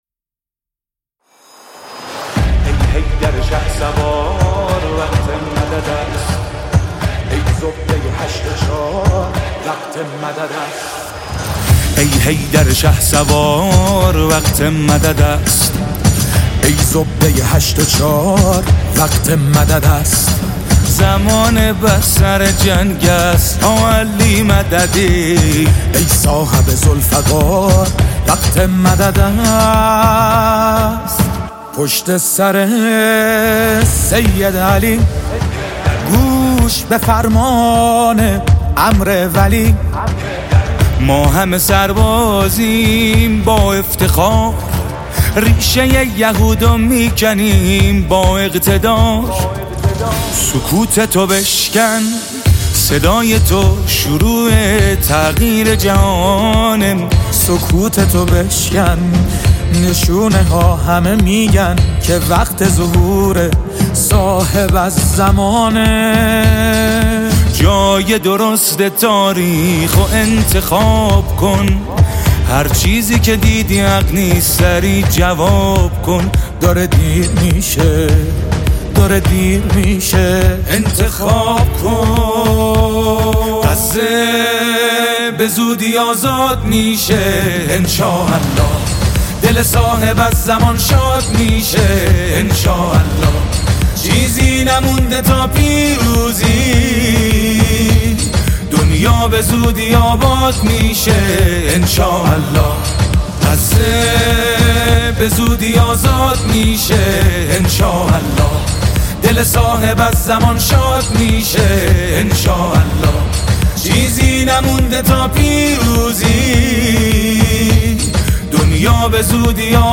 نماهنگ عربی زیبا و دلنشین